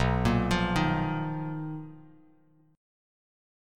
BM#11 chord